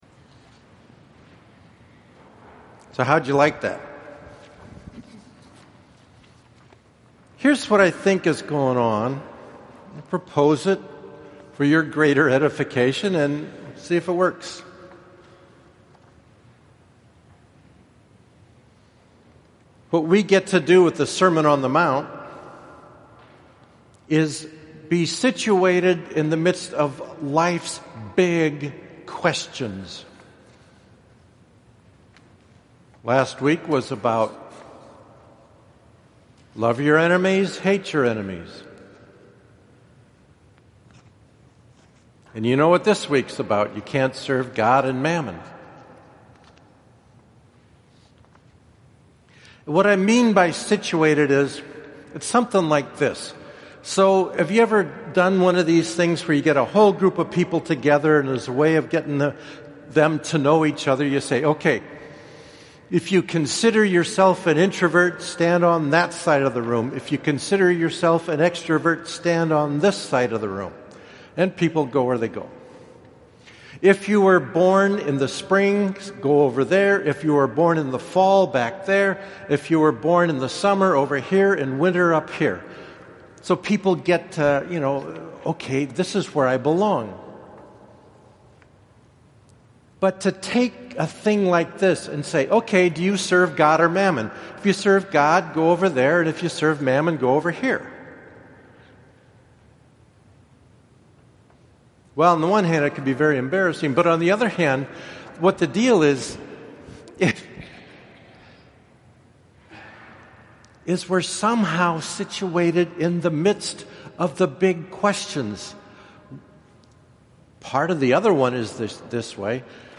8th SUN ORD – Audio homily
As per request, here is the audio version of my homily for the 8th Sunday of Ordinary Time. Think of it as another preparation for the joyful season of Lent.